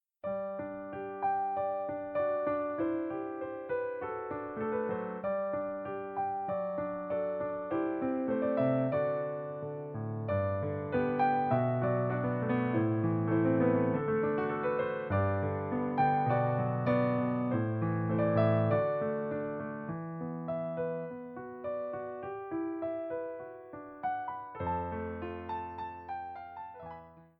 Seven evocative piano solos, minimalist in style.